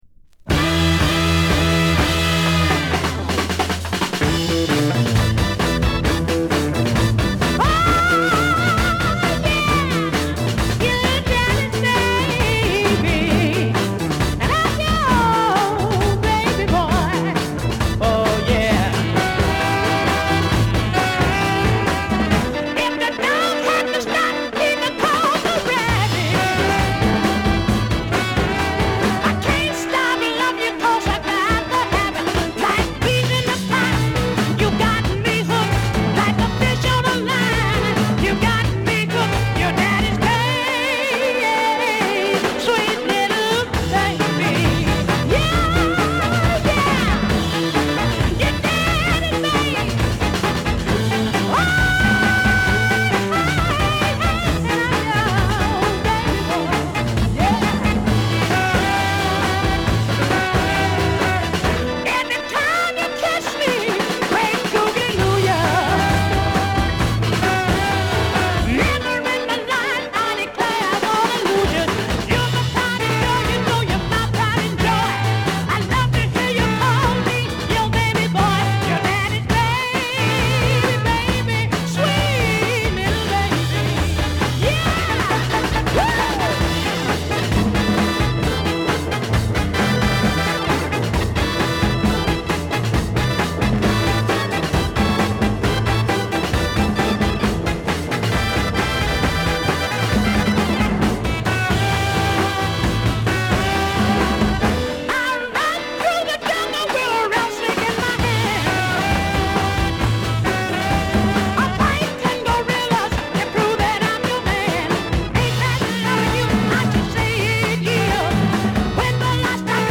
ベースリフでグイグイ引っ張るところは特有のもの。美しいファルセットが冴えるしなやかなR&Bソウルを聴かせる。